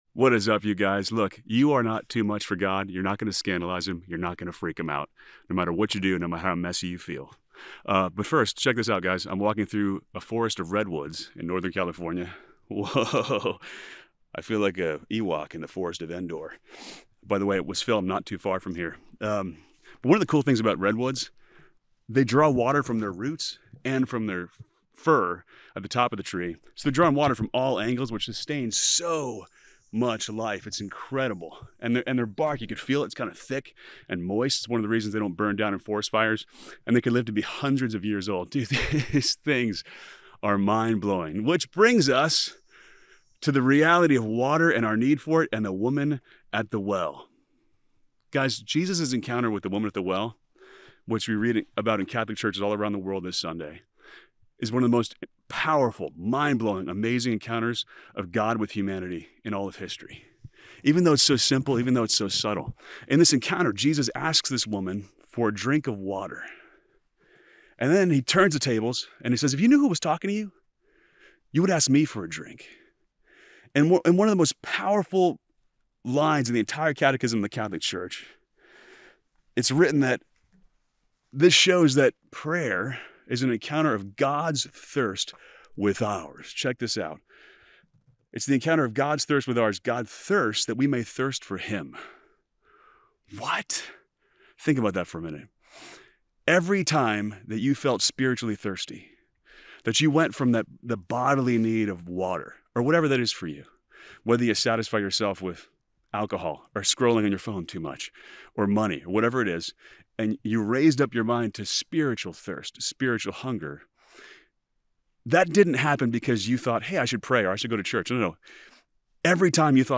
In this Gospel reflection, we dive into one of the most powerful encounters in all of Scripture: Jesus and the woman at the well.